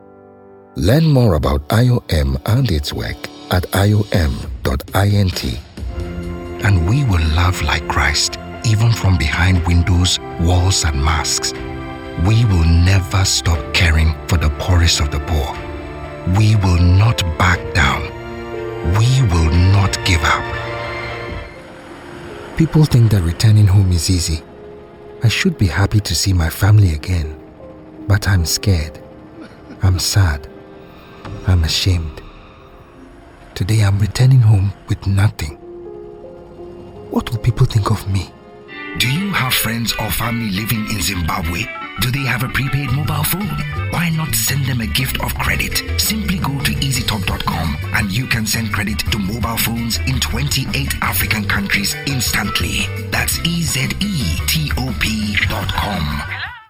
English (African)
Trustworthy
Warm
Conversational